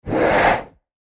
CloseHandheldTorch PE361703
Close Handheld Torch Whoosh, X6